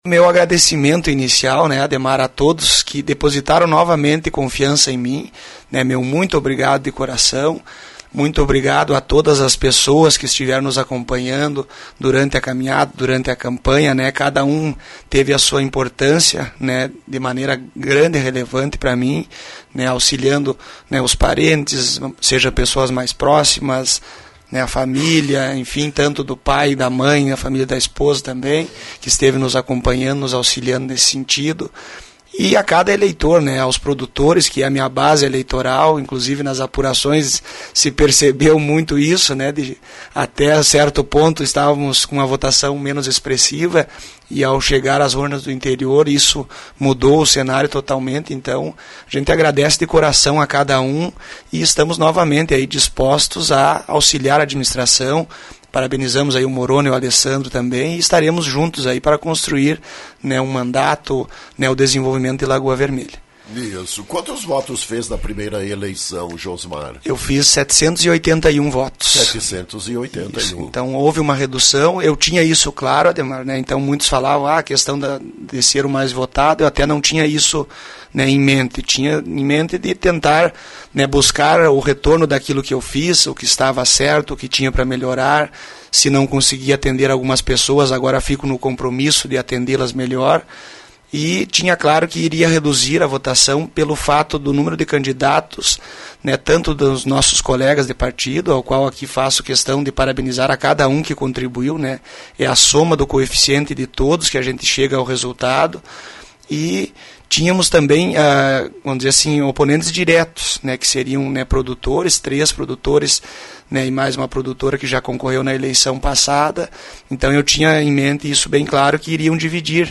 Josmar Veloso foi reeleito para um segundo mandato como vereador de Lagoa Vermelha. Foi o mais votado na eleição deste ano. Ouvido pela Rádio Lagoa FM, destacou suas principais proposições no atual mandato.